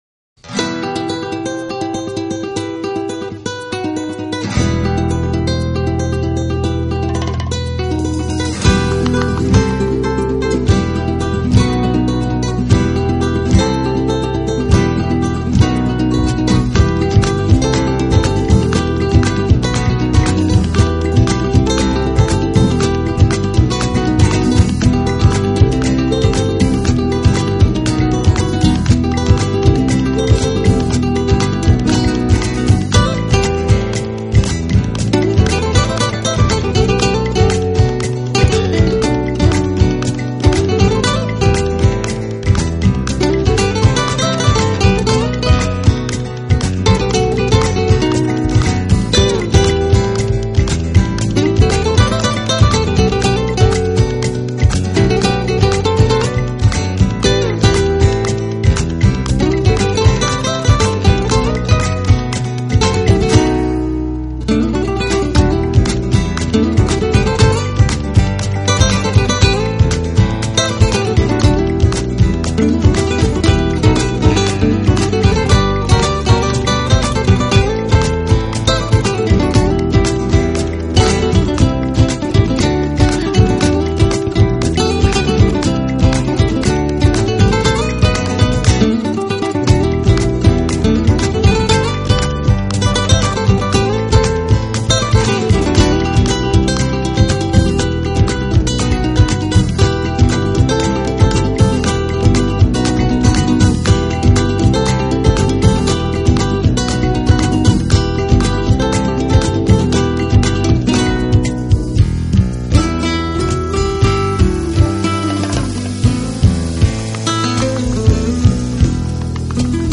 音乐风格主要是以拉丁-爵士为主。两人的吉他演奏技法精湛。